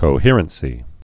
(kō-hîrən-sē)